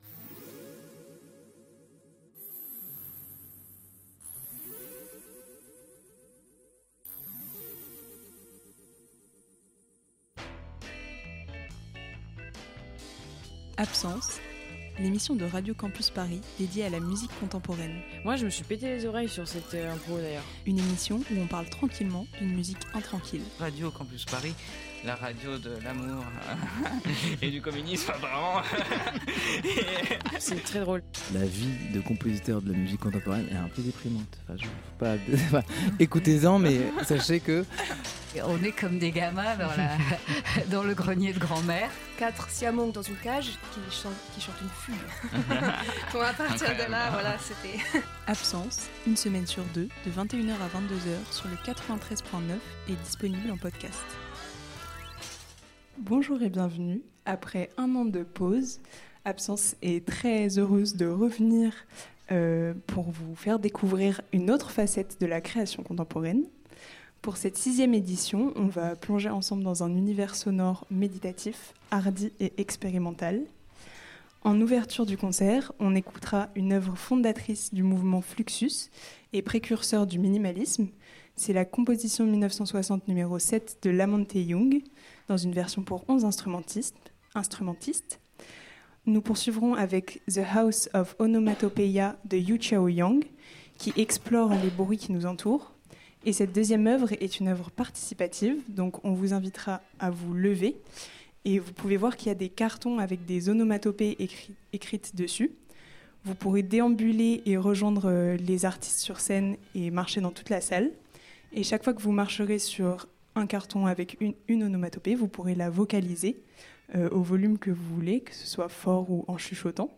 Trash Score Le tout pour 12 instrumentistes et quadriphonie !
En direct du festival absence /6 dans le 10e arrondissement à Paris !